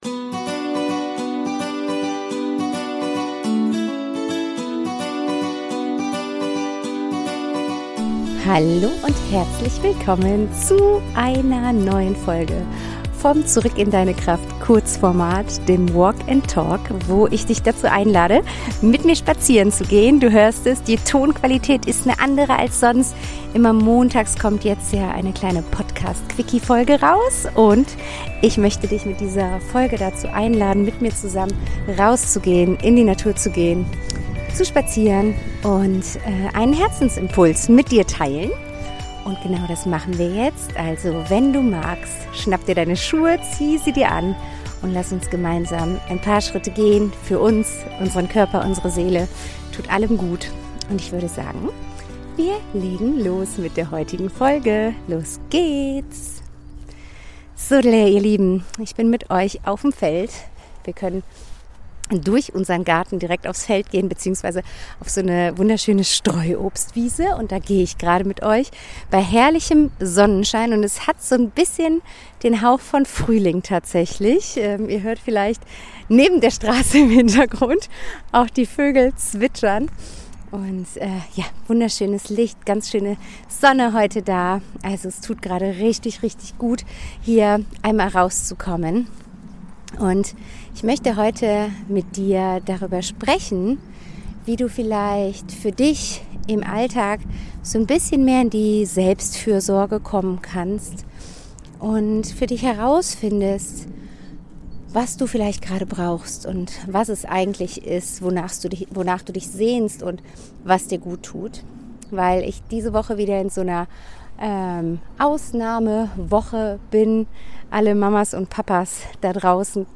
In diesen Folgen nehme ich dich mit auf einen Spaziergang. Beim Gehen teile ich spontane Gedanken, ehrliche Impulse und leise Fragen aus dem Moment heraus – unperfekt in der Tonqualität, dafür nah, authentisch und mitten aus dem Leben.